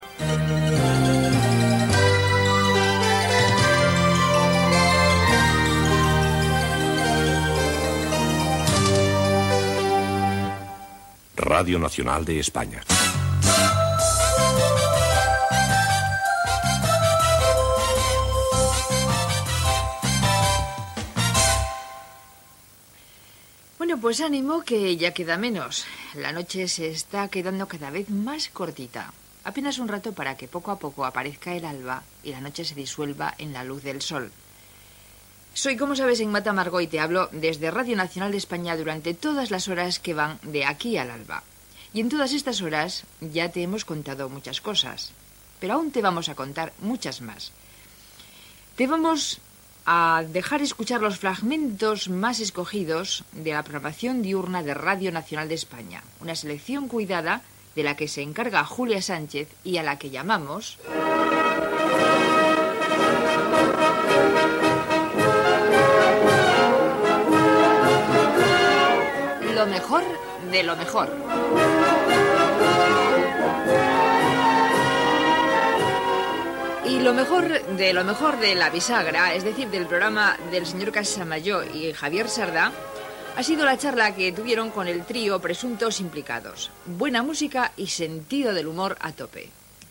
Sintonia de l'emissora, identificació, presentació del programa.